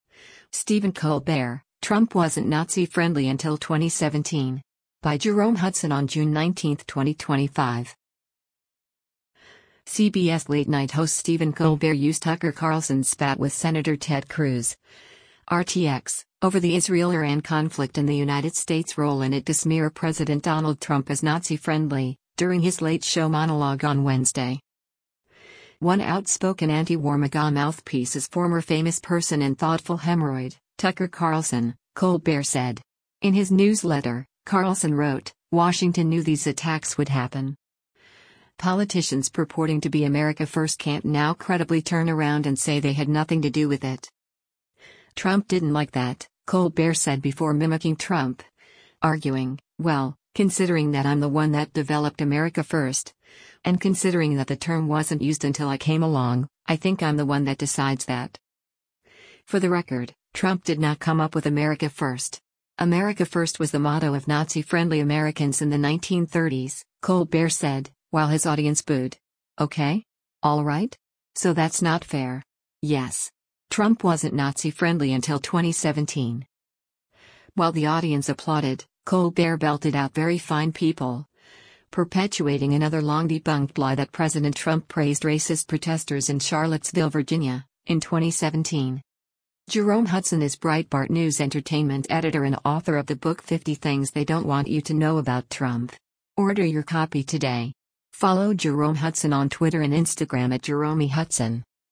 CBS late-night host Stephen Colbert used Tucker Carlson’s spat with Sen. Ted Cruz (R-TX) over the Israel-Iran conflict and the United States’ role in it to smear President Donald Trump as “Nazi-friendly,” during his Late Show monologue on Wednesday.
While the audience applauded, Colbert belted out “Very fine people,” perpetuating another long-debunked lie that President Trump praised racist protesters in Charlottesville Va., in 2017.